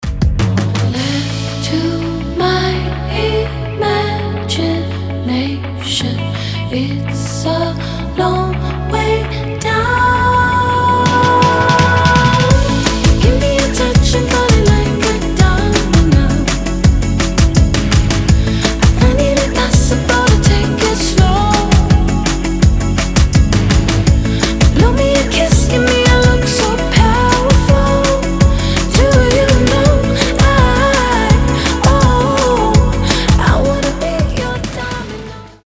• Качество: 320, Stereo
поп
женский вокал
заводные
dance
чувственные
красивый женский голос